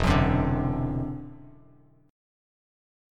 E7sus2#5 chord